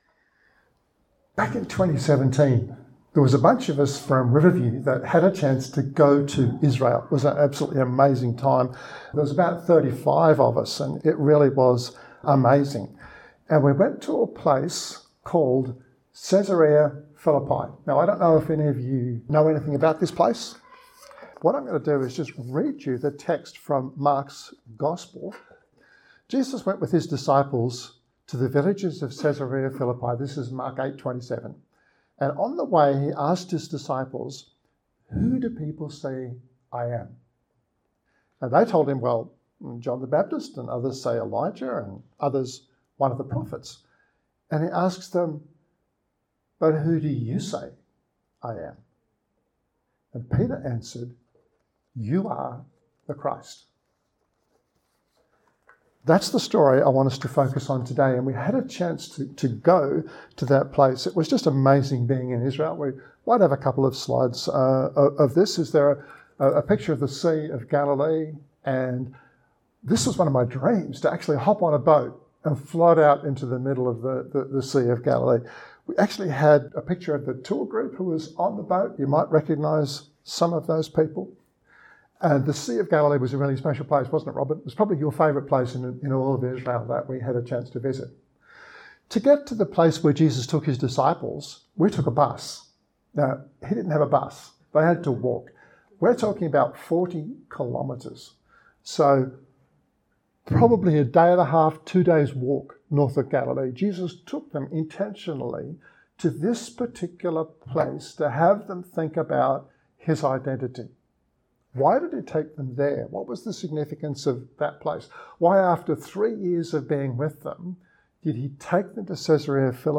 This podcast (24-minutes) is from the message delivered to Riverview Church’s Joondalup campus on 8 August 2021. https